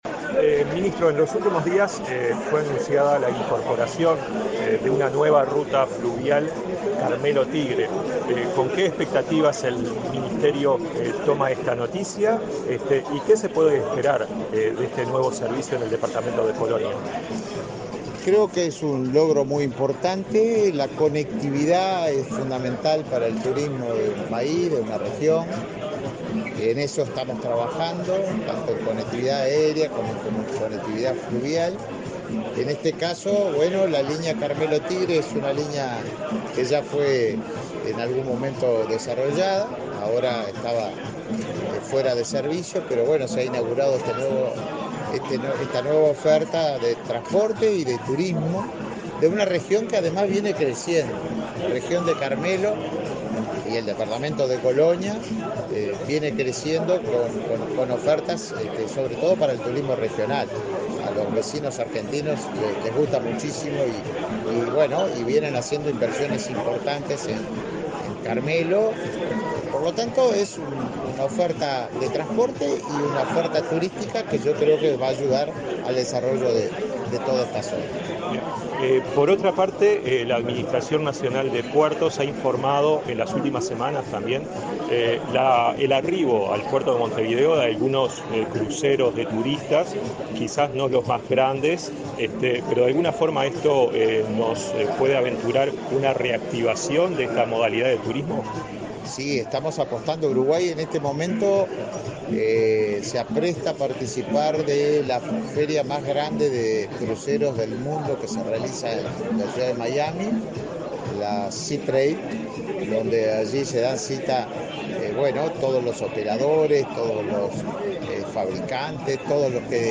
Entrevista al ministro de Turismo, Tabaré Viera